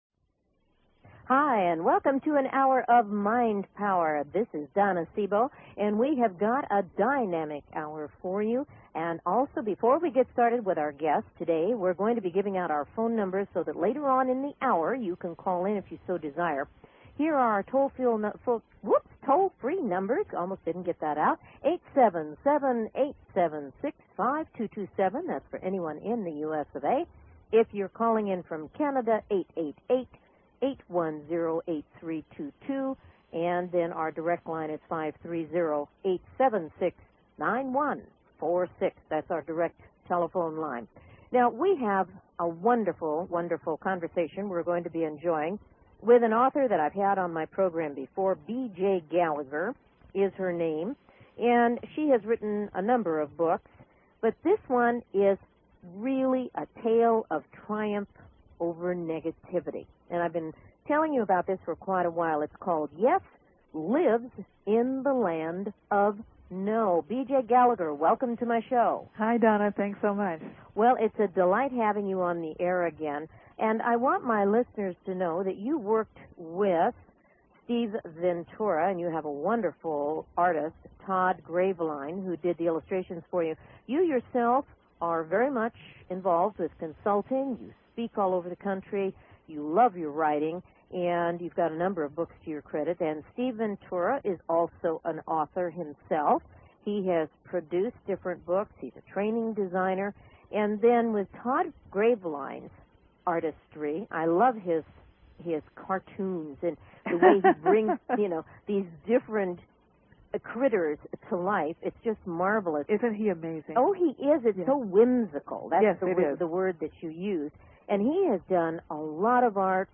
If you are wanting to know how to make rejections work for you, don't miss this interview!